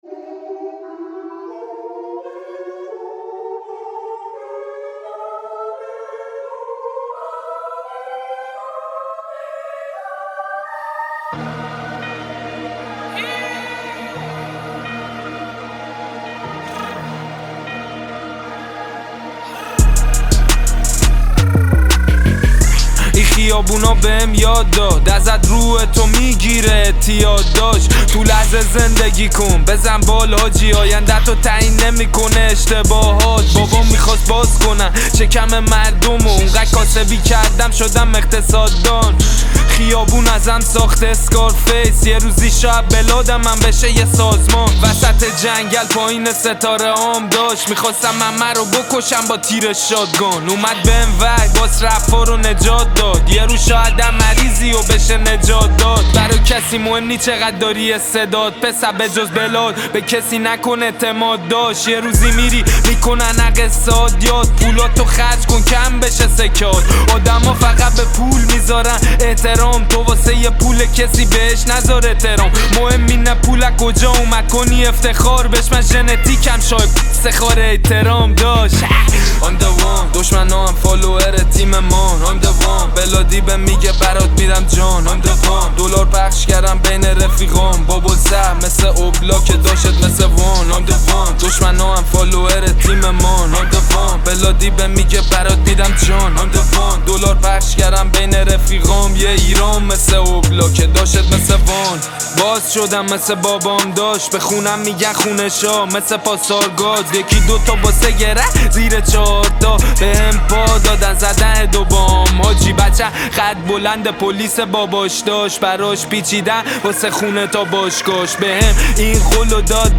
عاشقانه و احساسی